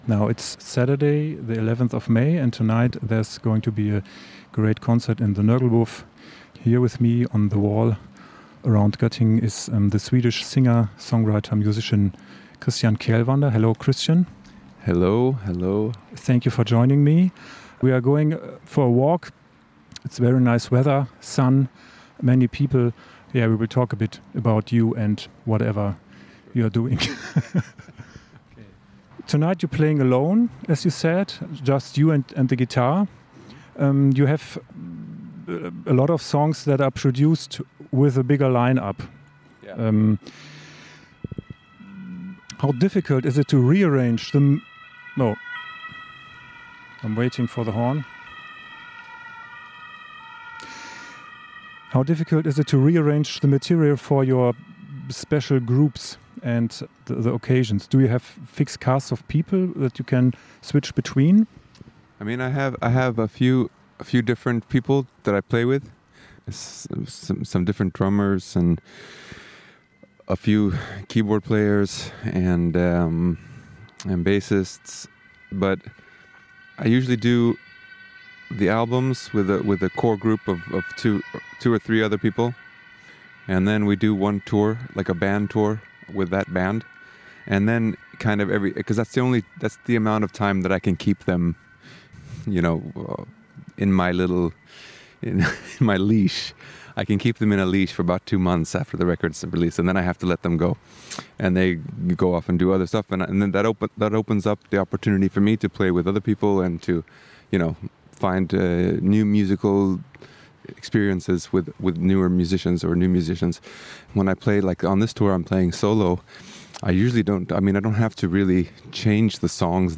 A talk on a walk: Interview